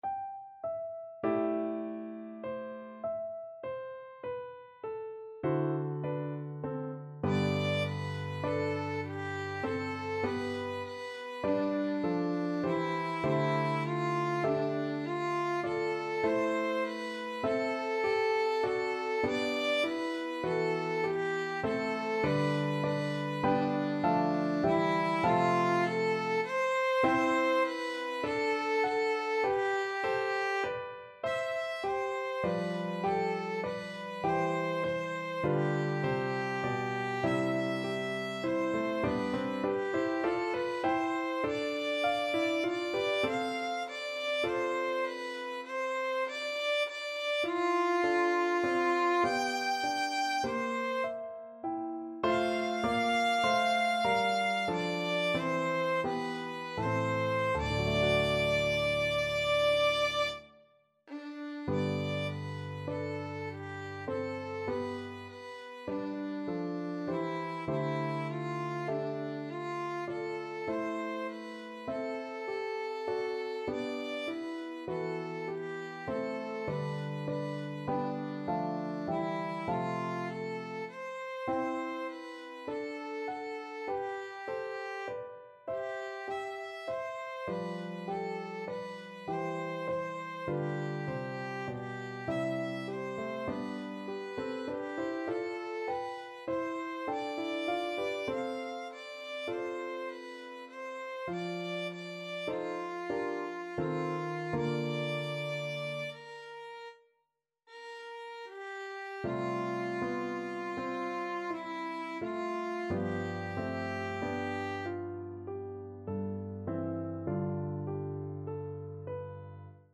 5/4 (View more 5/4 Music)
Classical (View more Classical Violin Music)